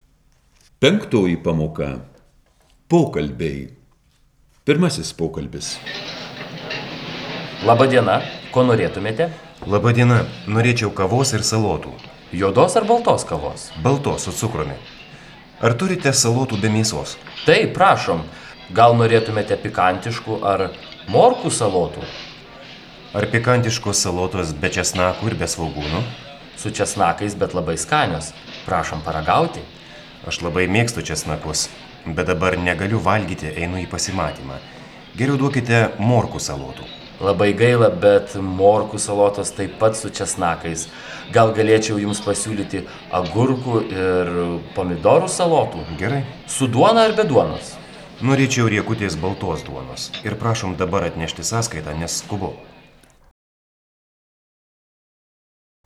05_Dialog_1.wav